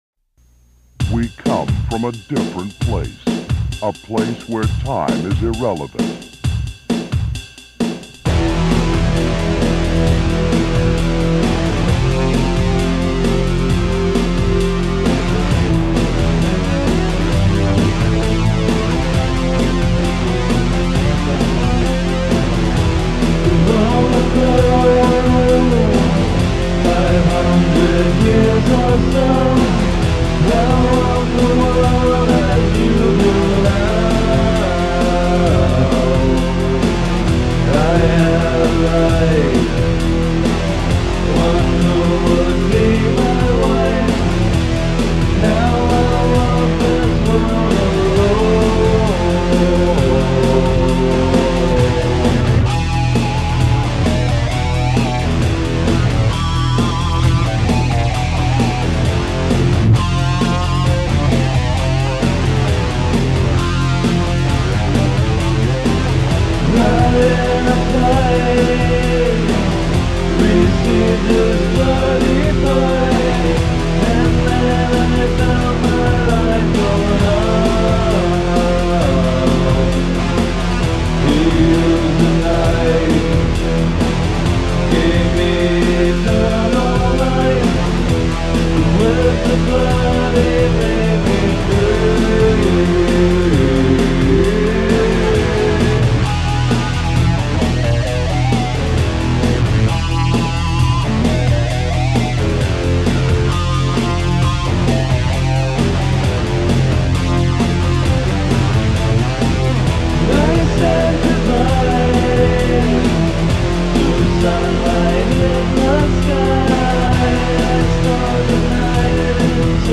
Music is another of my creative interests I write lyrics, I play guitar, I sing, I do all that over mechanical drums on a recorder and create songs. I have made over 100 song demos of verious quality levels over the years depending on what kind of equiptment I had to work with.
The song playing is one of my original recordings.